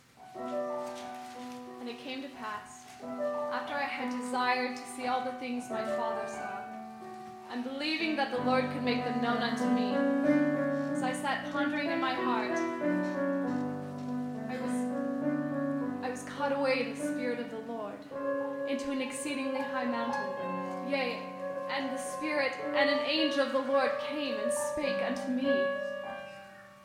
Soprano and Piano